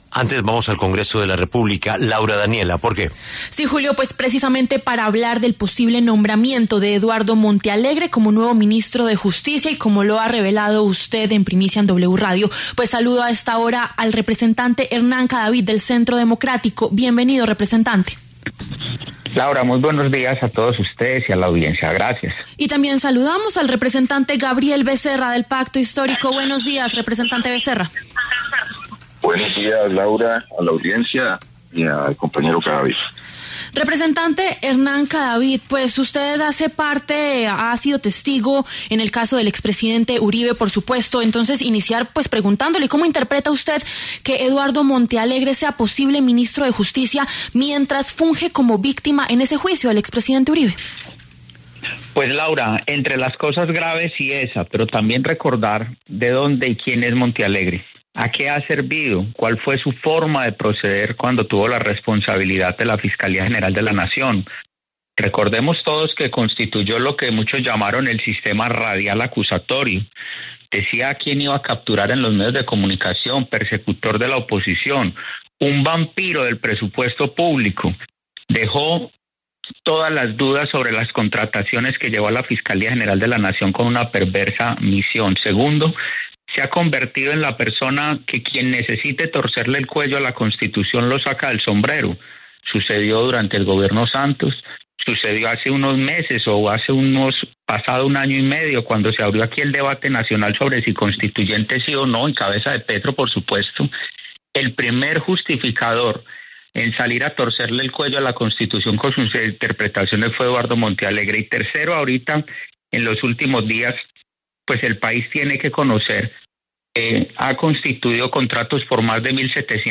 Los representantes Hernán Cadavid, del Centro Democrático, y Gabriel Becerra, del Pacto Histórico, pasaron por los micrófonos de La W.